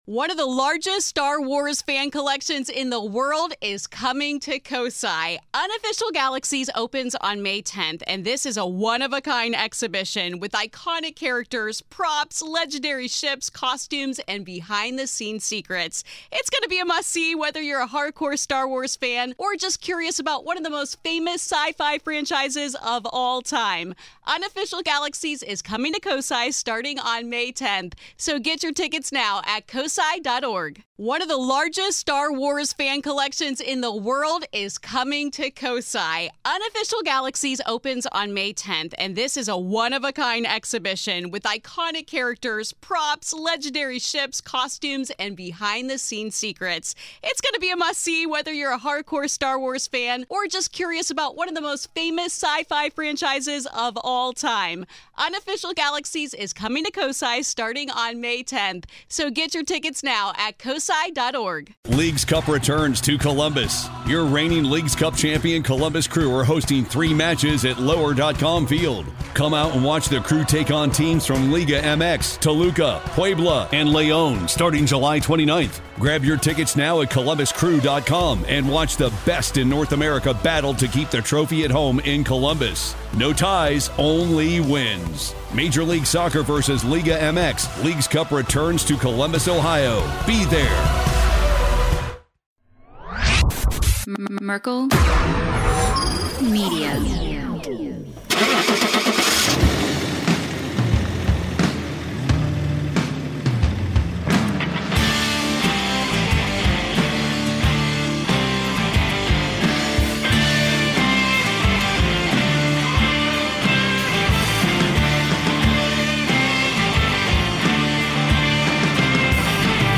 In this week's episode, the guys begin their descent into madness as they chat about everything from truck crashes to hotdogs!